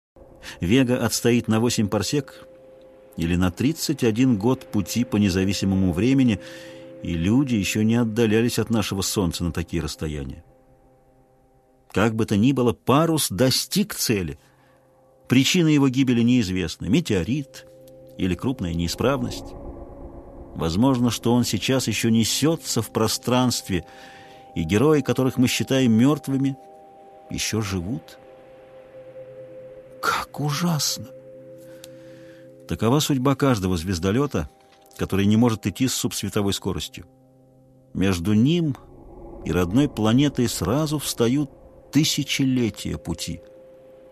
Диалектика интересная тема, интересуюсь ей но пока только записями со стримов товарищей да вот решил на эту тему аудиокнигу так называемую послушать "Туманность андромеды" Ефремова. Собственно тот фрагмент который я обещал выложить позже - из этой аудиокниги. Может быть он вам покажется простым но я пока догадываясь что у этого фонового звука высокая октава и длинная атака.